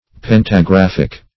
Search Result for " pentagraphic" : The Collaborative International Dictionary of English v.0.48: Pentagraphic \Pen`ta*graph"ic\, Pentagraphical \Pen`ta*graph"ic*al\, a. [Corrupted fr. pantographic, -ical.]